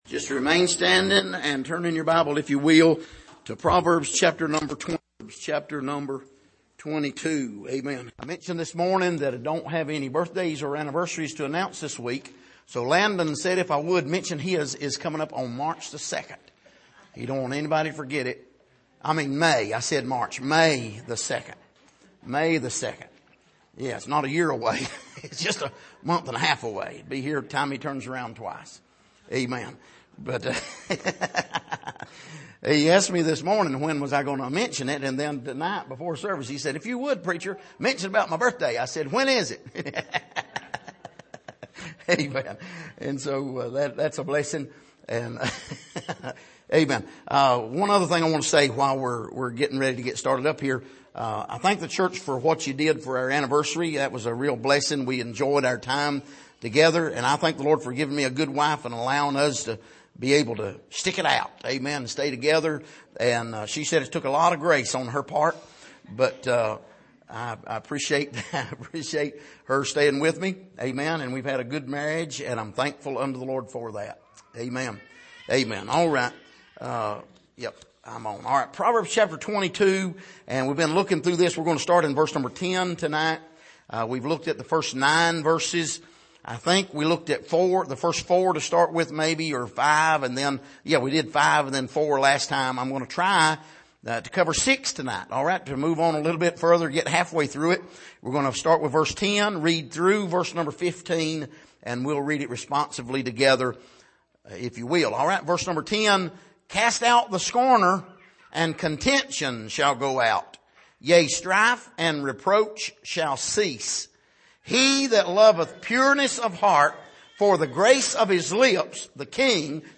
Passage: Proverbs 22:10-15 Service: Sunday Evening